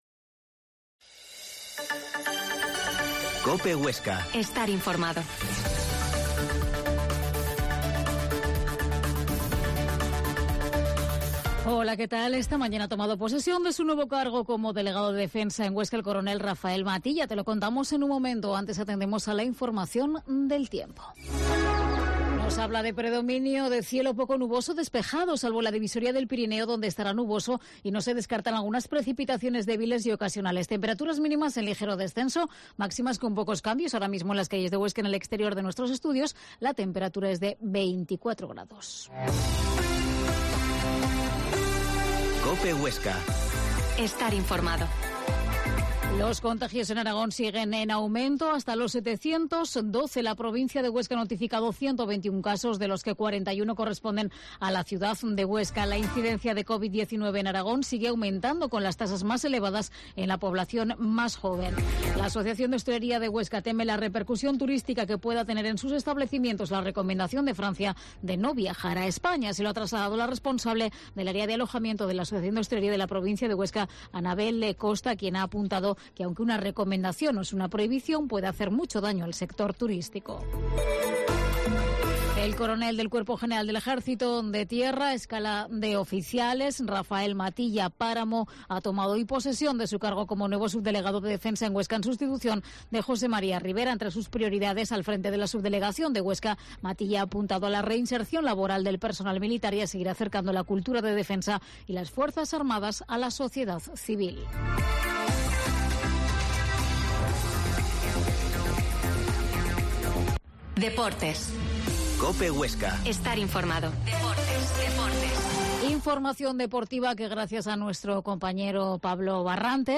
Mediodía en COPE Huesca 13.20h. Entrevista a la responsable de Cultura de la Hoya de Huesca, Beatriz Calvo